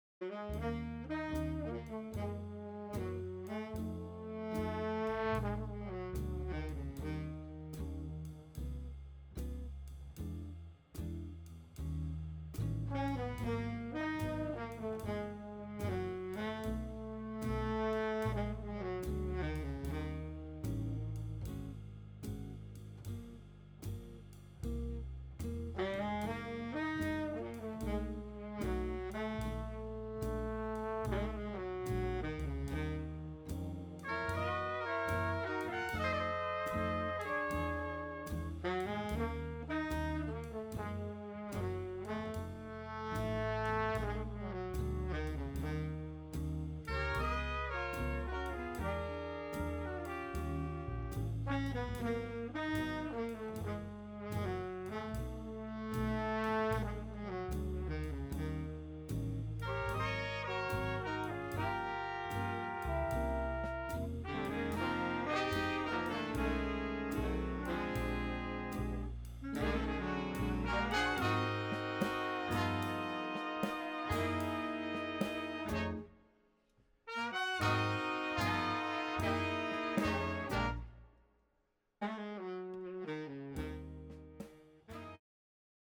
Recorded Master Chord Studios January 2017